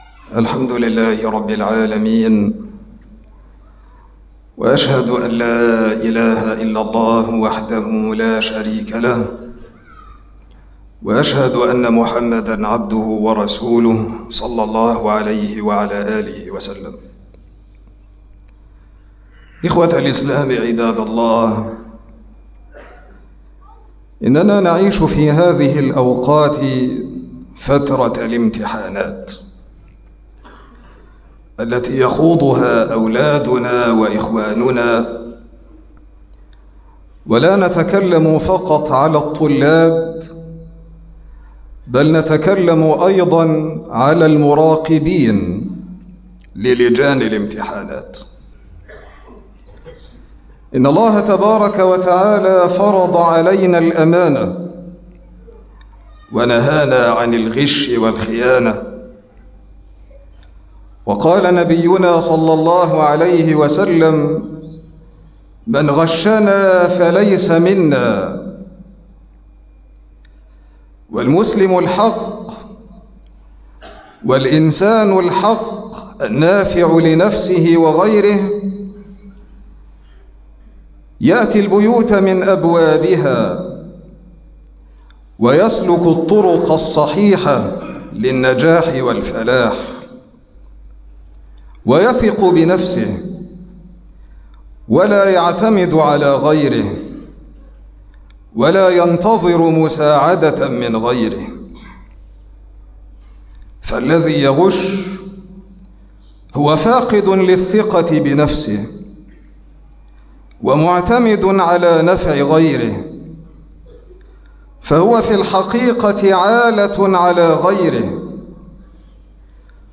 مقطع من خطبة الجمعة